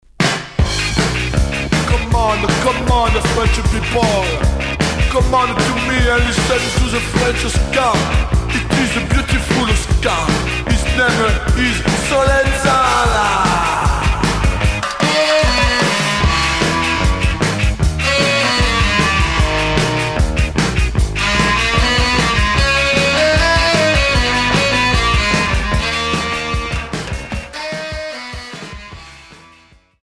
Ska Unique 45t retour à l'accueil